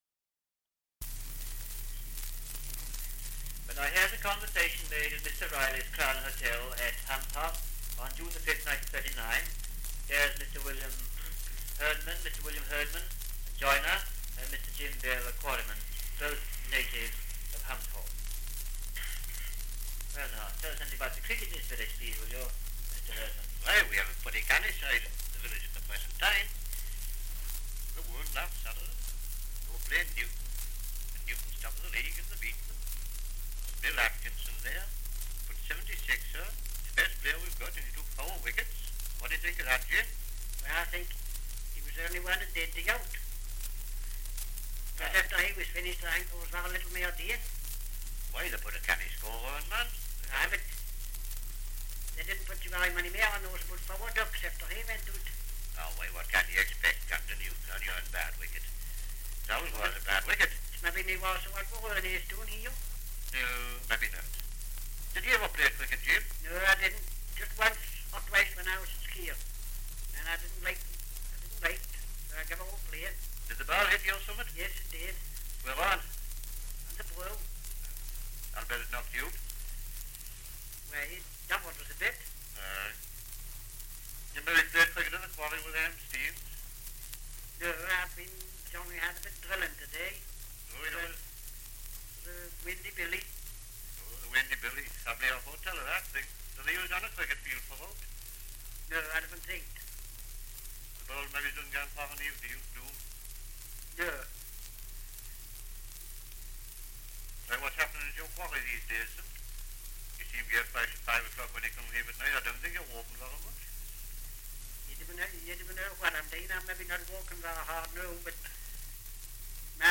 Dialect recording in Humshaugh, Northumberland
78 r.p.m., cellulose nitrate on aluminium